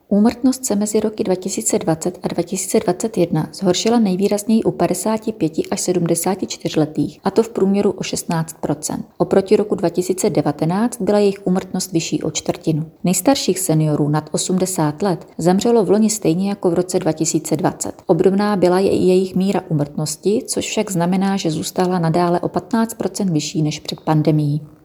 Vyjádření Marka Rojíčka, předsedy ČSÚ, soubor ve formátu MP3, 692.9 kB